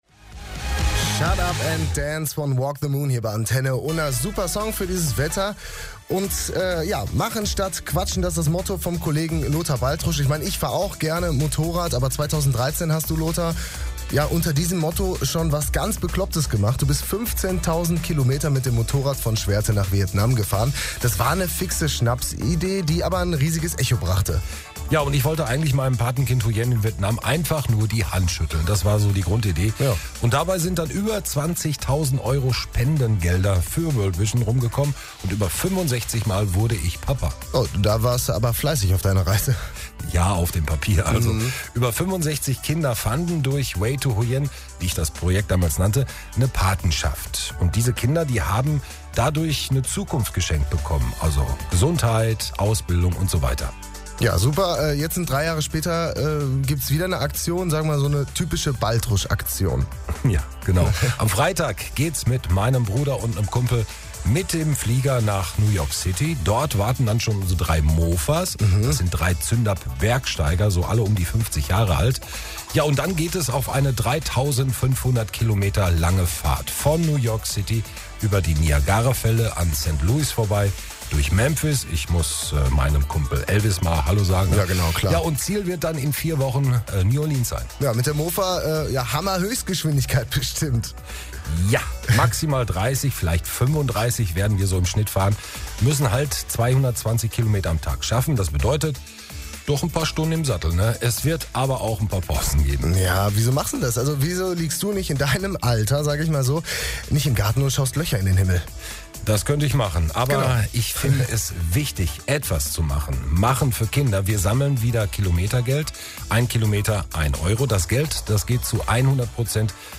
Der Startschuss ist gefallen. Beitrag am 08.Mai 2016 bei Antenne Unna.